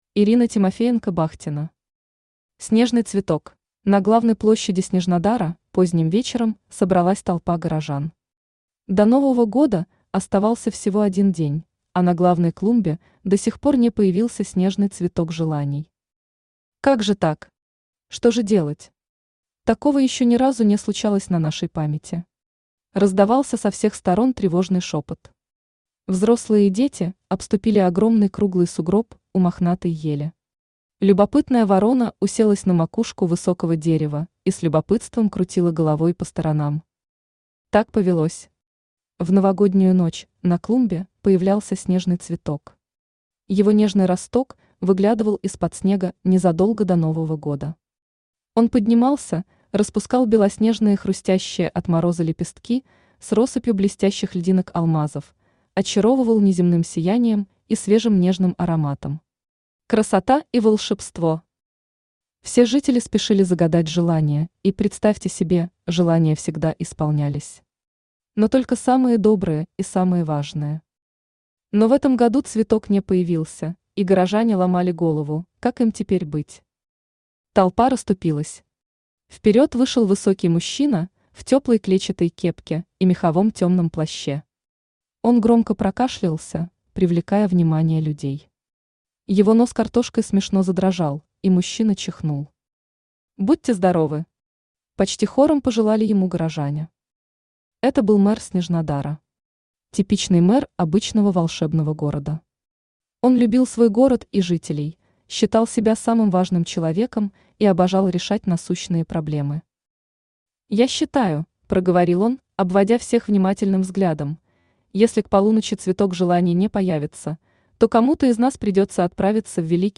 Аудиокнига Снежный цветок | Библиотека аудиокниг
Aудиокнига Снежный цветок Автор Ирина Тимофеенко-Бахтина Читает аудиокнигу Авточтец ЛитРес.